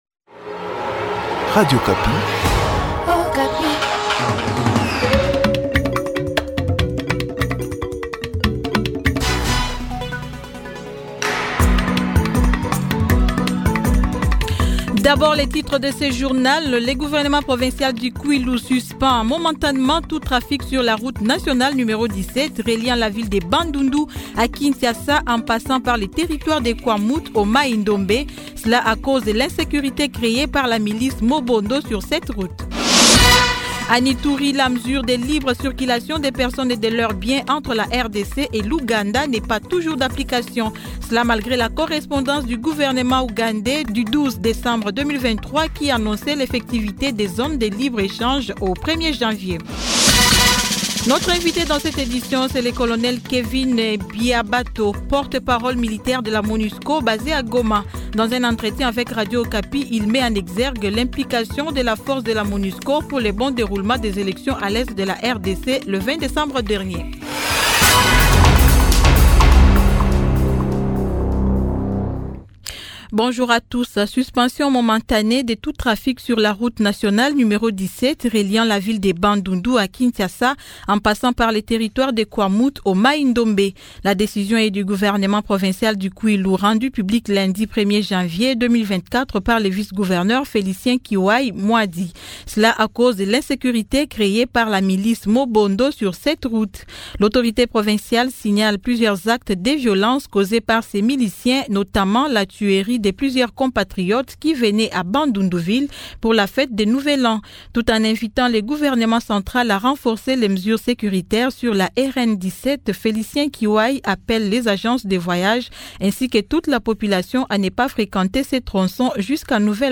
Journal midi
CONDUCTEUR DU JOURNAL PARLE MIDI DU 02 JANVIER 2024